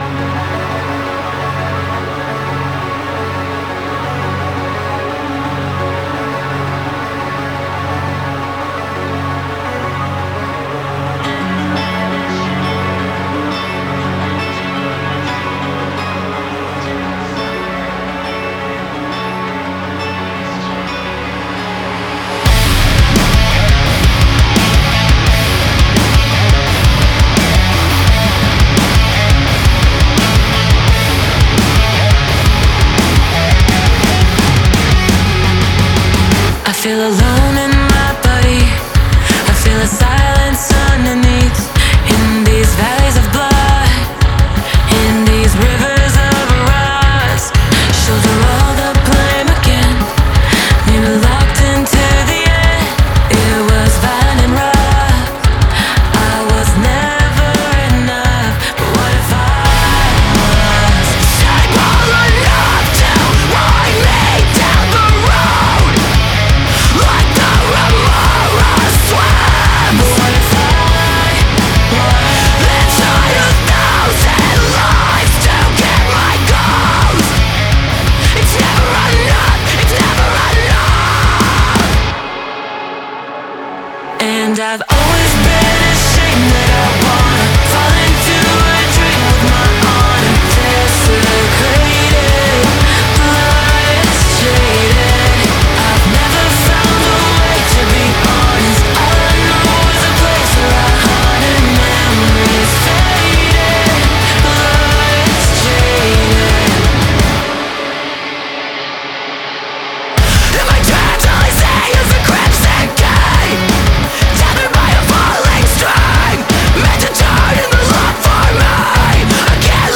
Metal
وکالیستش خیلی خوبه هم اهنگش ارومه و هم خشنه.